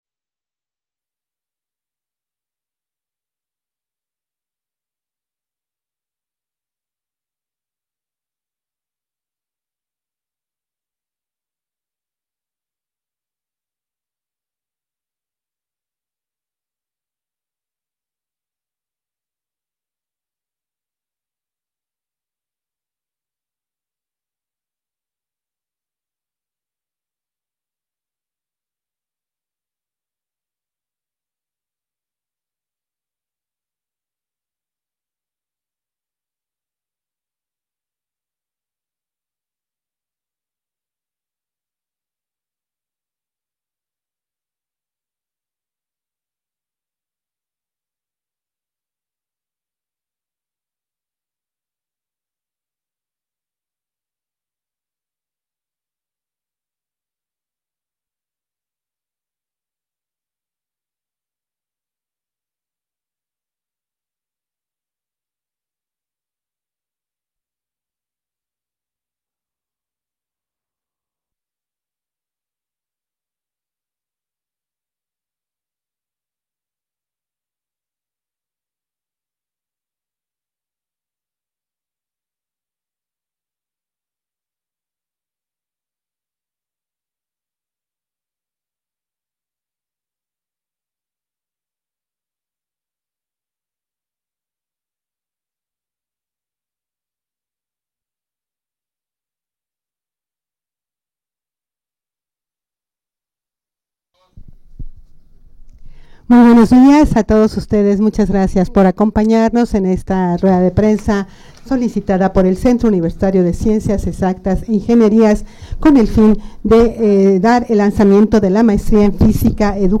Audio de la Rueda de Prensa
rueda-de-prensa-lanzamiento-de-la-maestria-en-fisica-educativa-de-cucei-calendario-2025-b.mp3